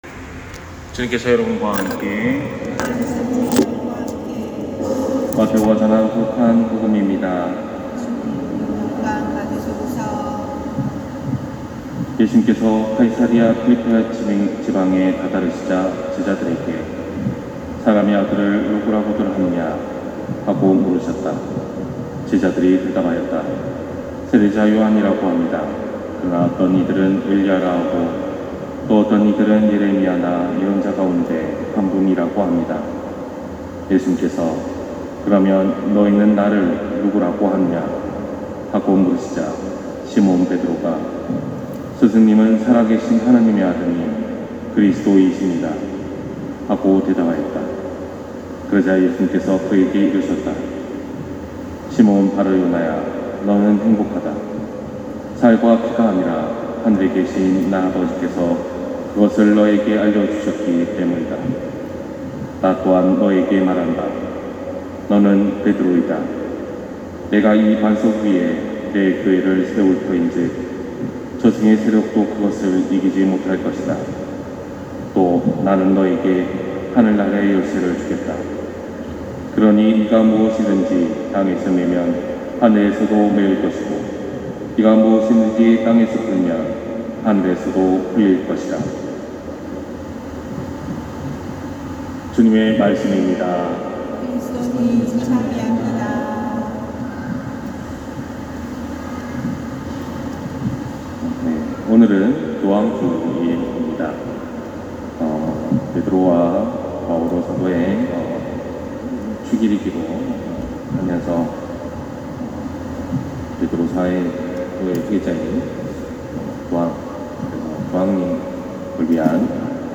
250628신부님 강론말씀